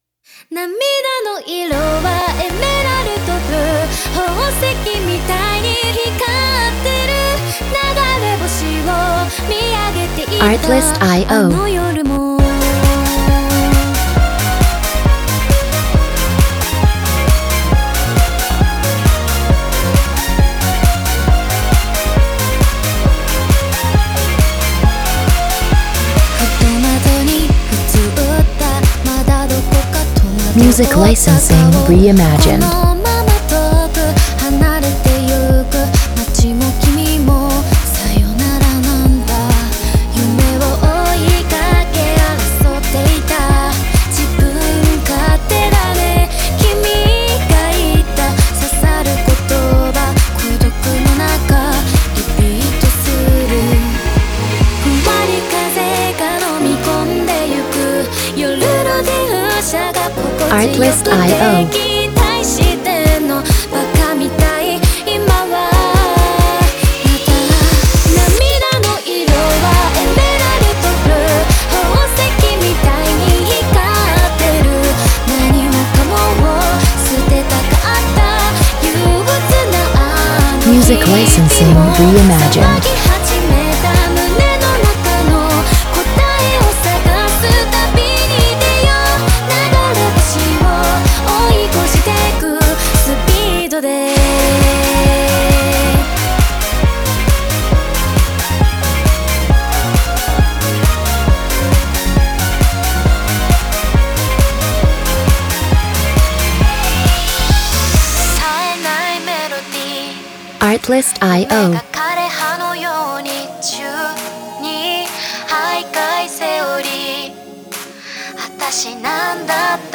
bright, upbeat J-Pop tracks for anime films